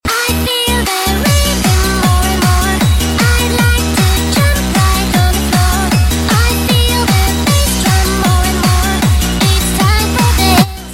Hehe.. Sound Effects Free Download